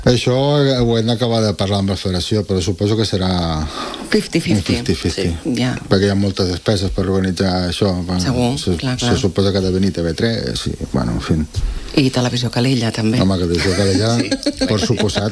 A més, els directius de la UEHC han passat aquesta setmana pels estudis de Ràdio Calella TV per parlar de la designació de Calella com a Ciutat de l’Handbol Català per als anys 2026 i 2027.